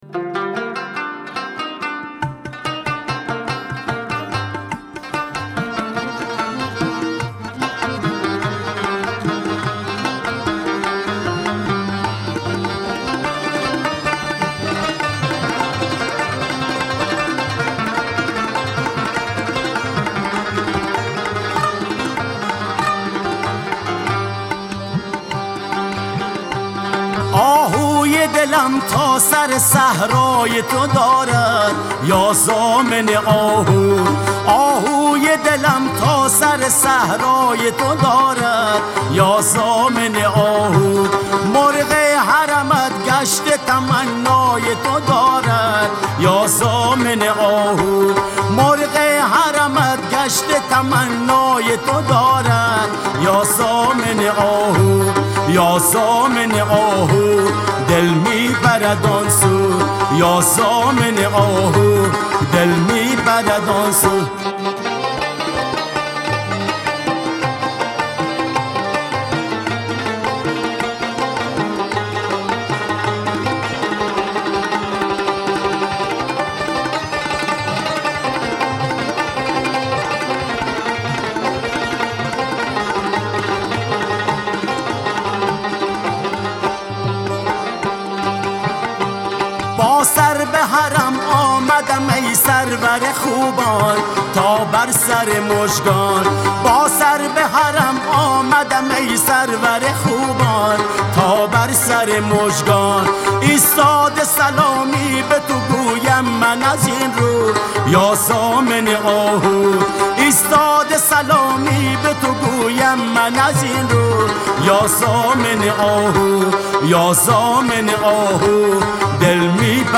برگرفته از قوالی ها و مناقب خوانی مکتب هرات (افغانستان)